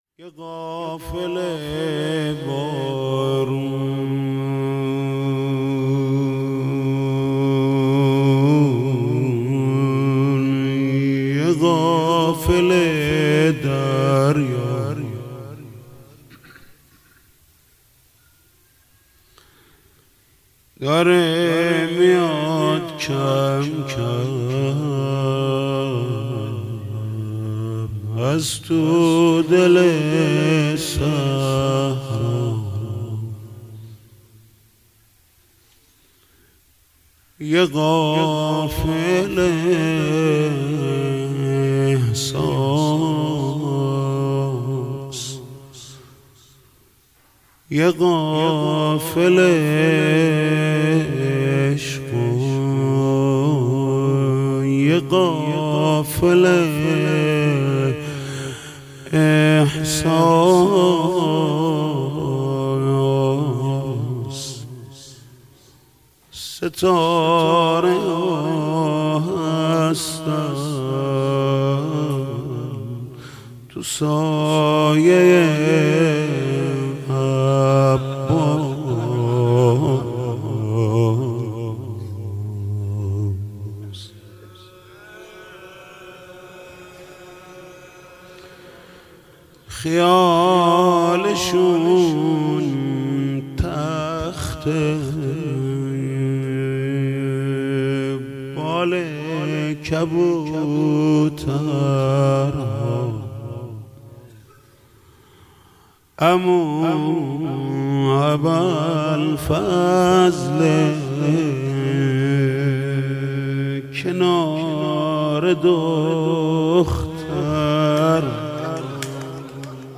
مداحی محمود کریمی در شب دوم ماه محرم 1398 - تسنیم
صوت مداحی محمود کریمی در دومین شب از ماه محرم در مسجد الهادی منتشر شد.